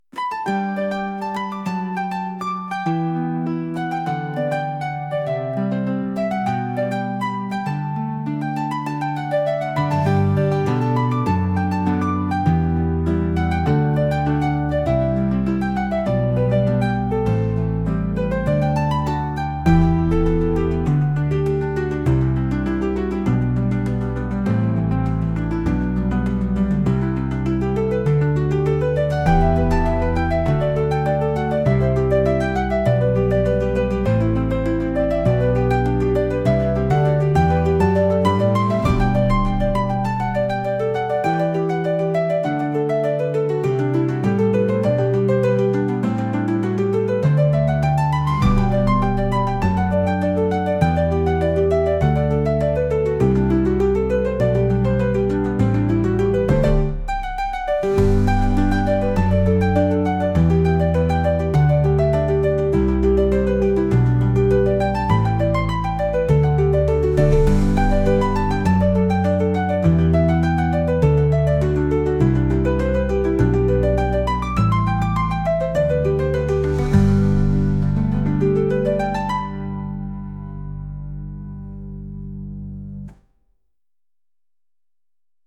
春の日差しにワクワクするようなさわやかなピアノ曲です。